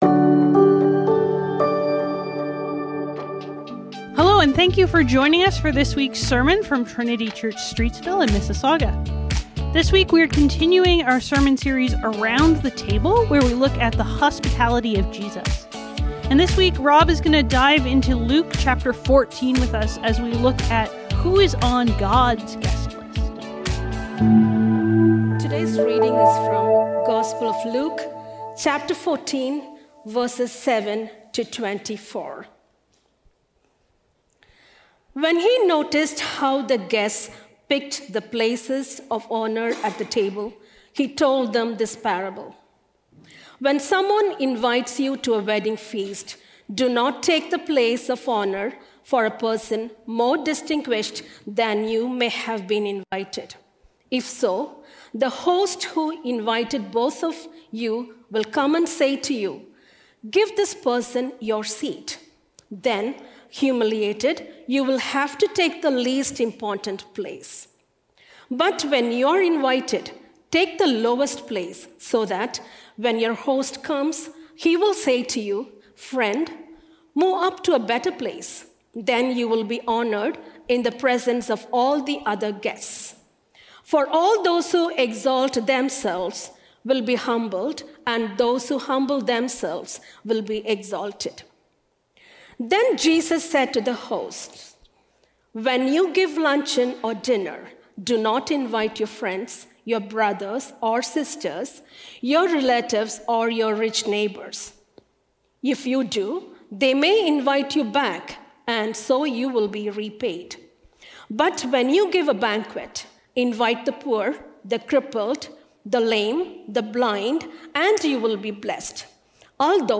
Trinity Streetsville - God’s Guest List | Around The Table | Trinity Sermons